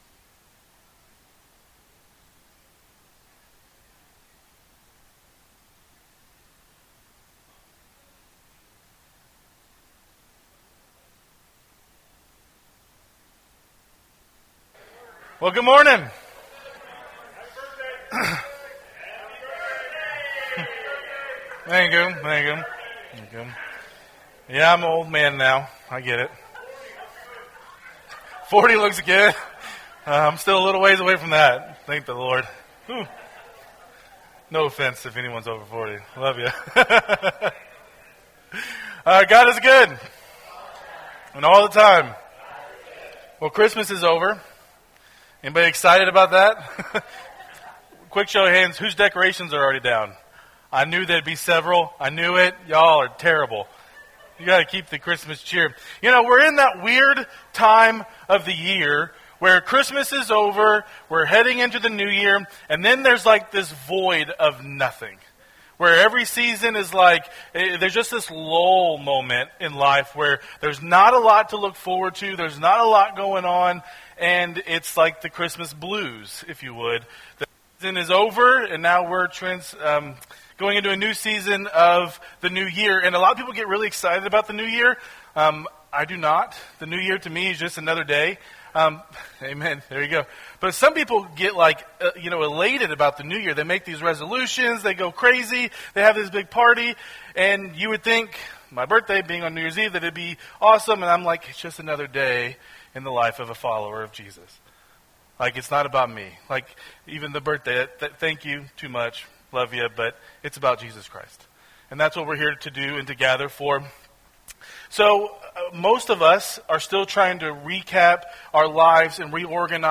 Sermons | Christian Covenant Fellowship